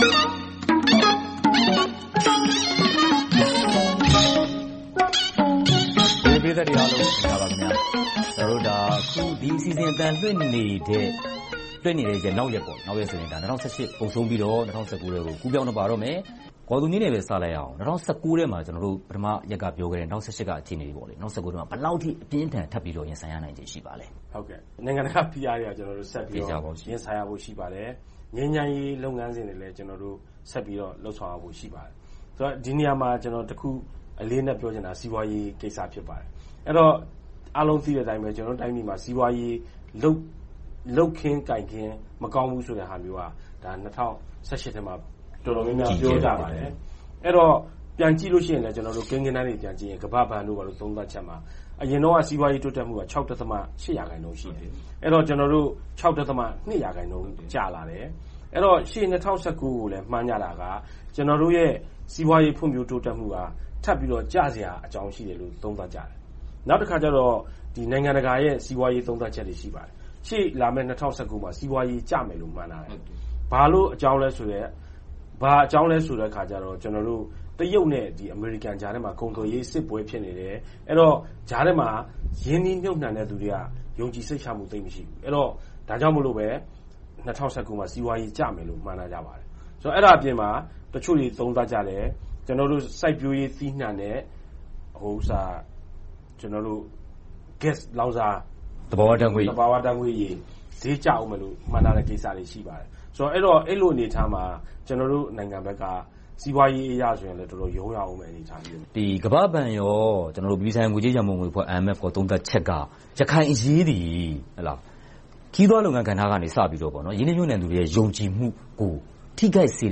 ရန်ကုန် VOA စတူဒီယိုမှာ သုံးသပ်ဆွေးနွေးထားပါတယ်။